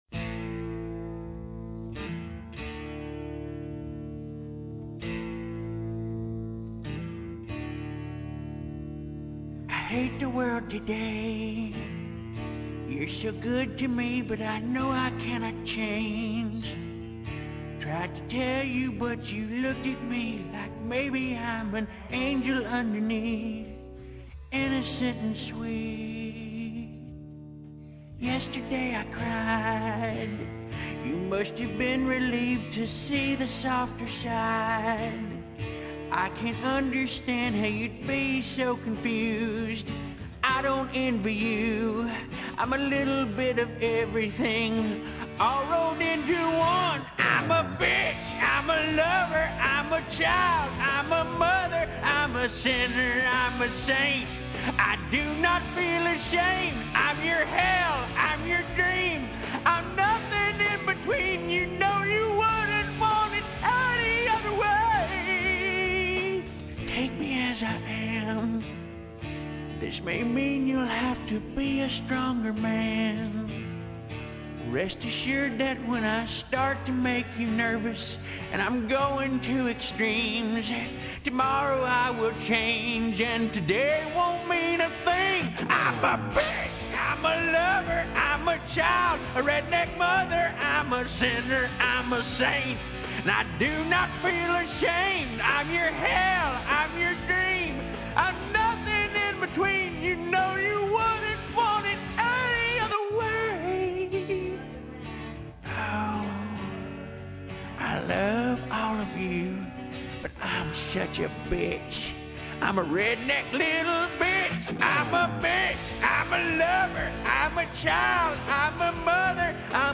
very angry feminine song